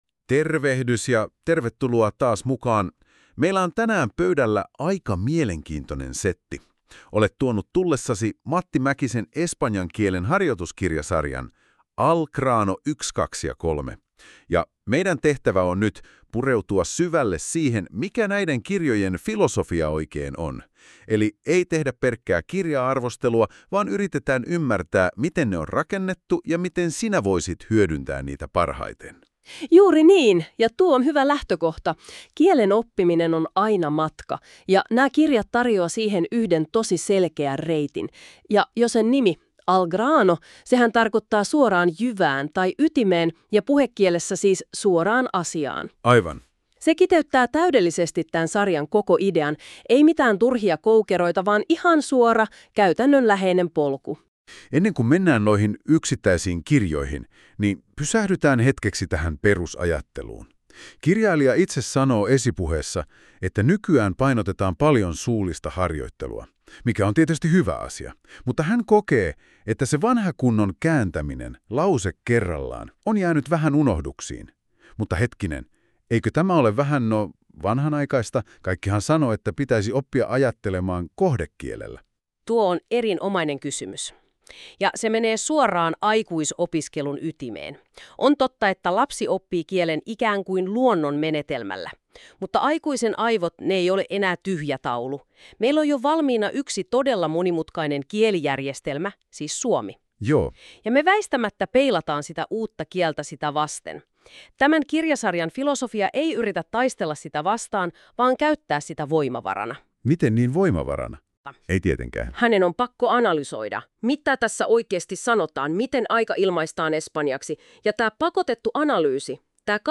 Alla tekoälyn luoma yleiskatsaus kirjoihin.